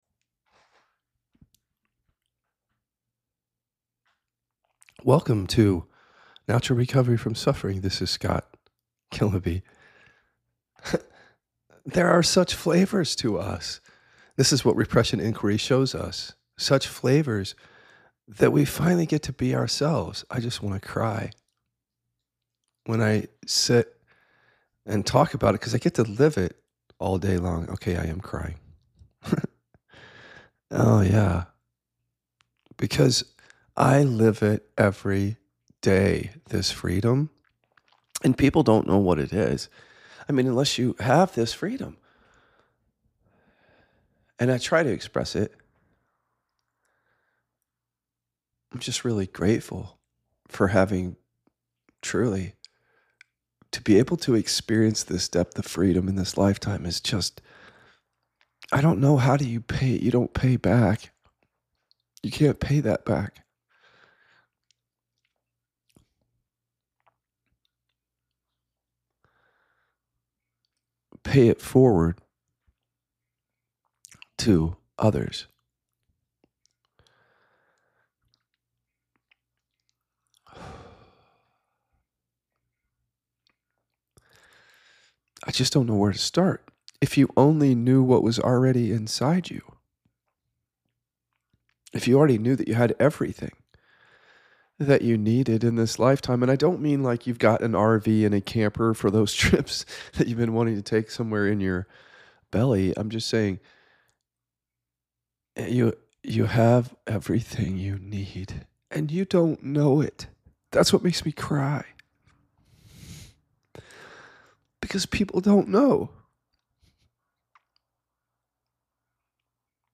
breaks down and cries in this episode